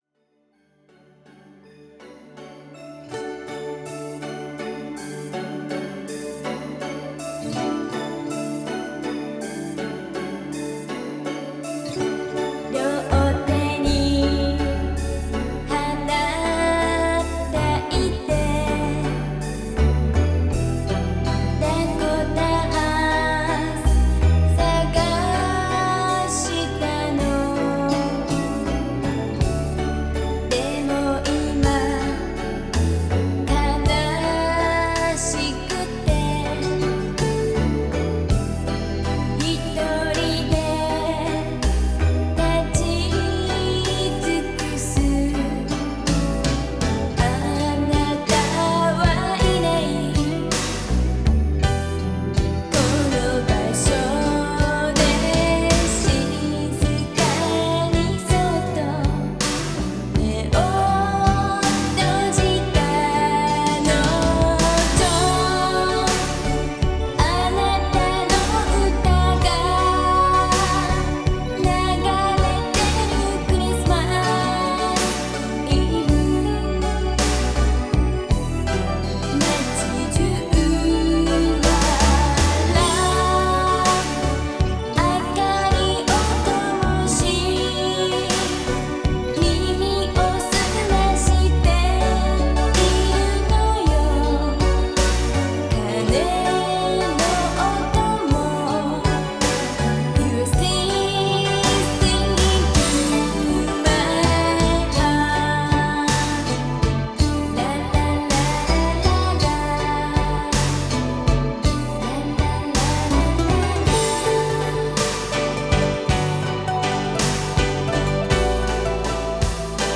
ジャンル(スタイル) JAPANESE POP / R&B